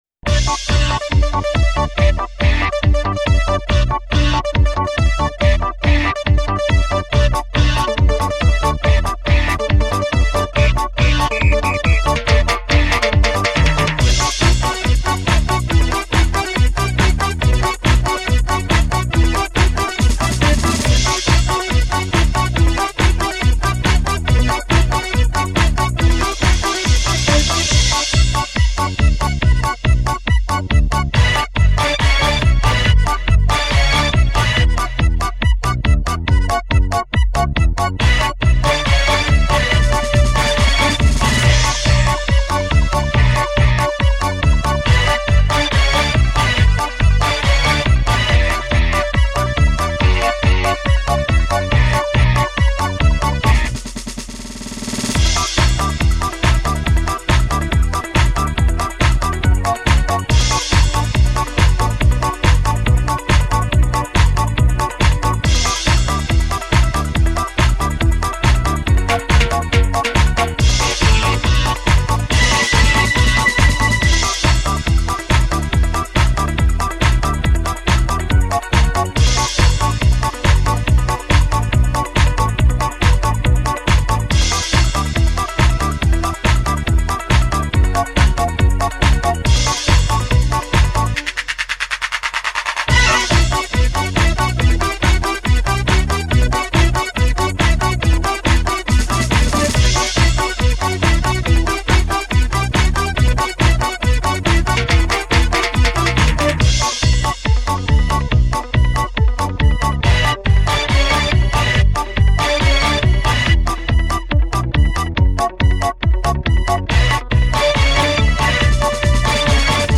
mp3 Скачать минус Похожие Смотри ещё